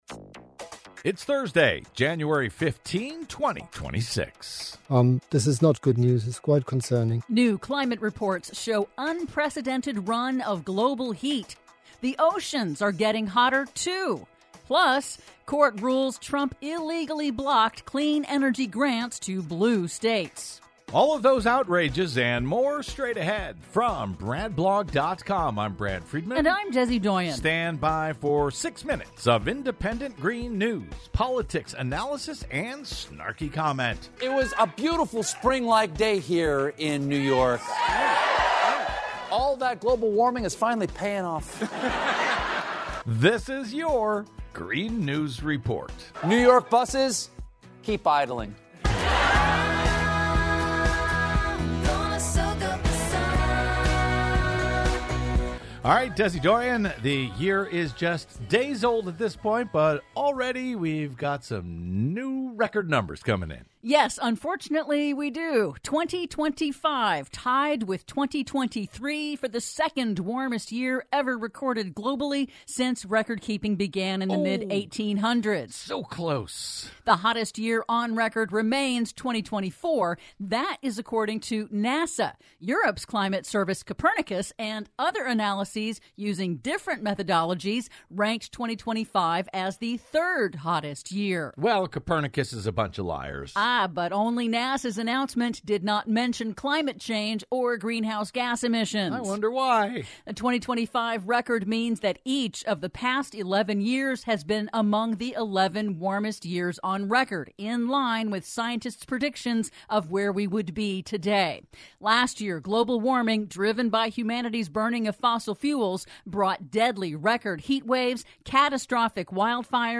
IN TODAY'S RADIO REPORT: 2025 was the second (or third) hottest year on record, as new climate reports show 'unprecedented run of global heat'; The oceans are getting hotter, too; California is free of drought for the first time in 25 years; PLUS: Court rules Trump Administration illegally blocked clean energy grants to 'blue' states... All that and more in today's Green News Report!